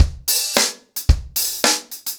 DaveAndMe-110BPM.9.wav